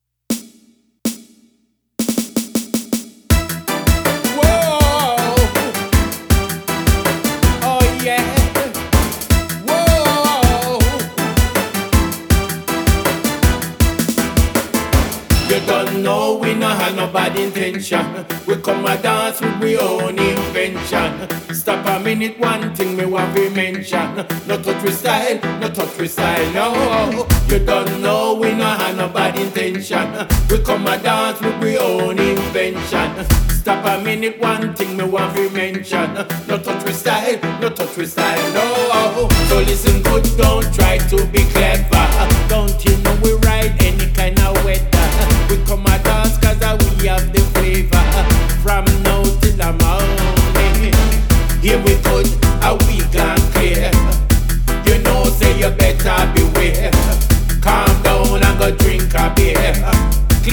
on vocal!
bringing us an upbeat riddim, mellow and heavy!